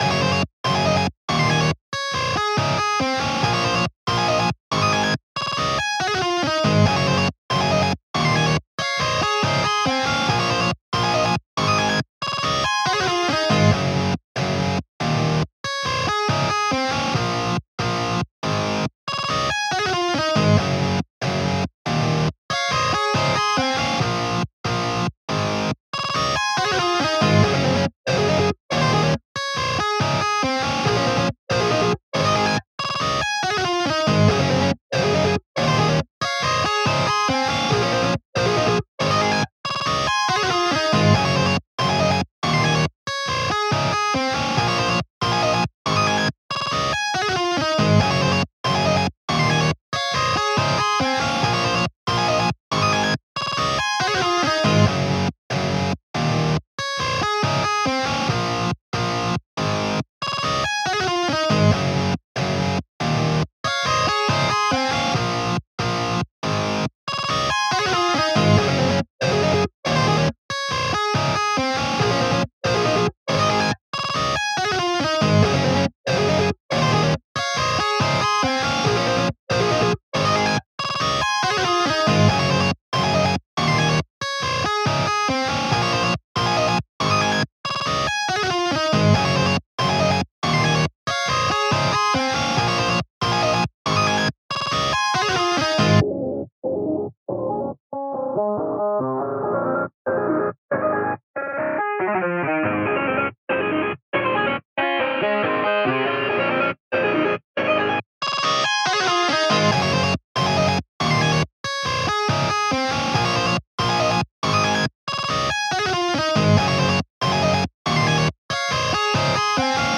2. Hip Hop Instrumentals